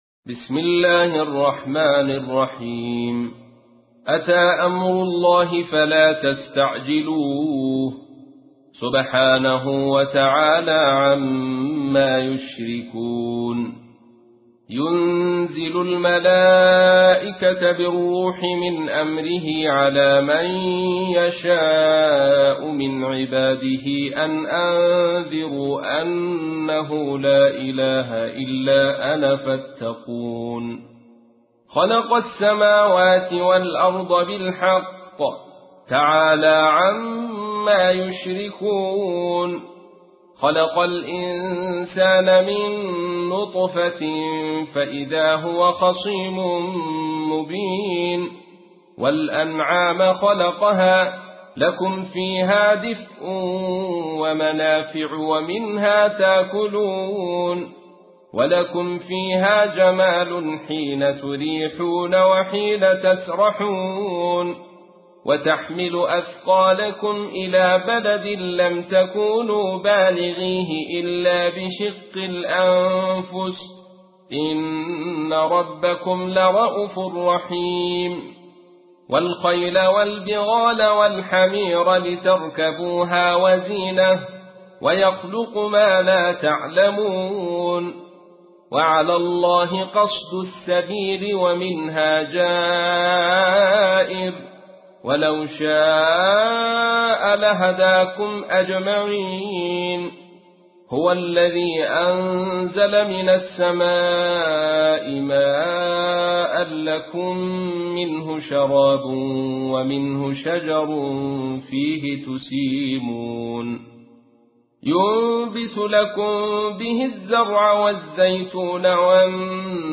تحميل : 16. سورة النحل / القارئ عبد الرشيد صوفي / القرآن الكريم / موقع يا حسين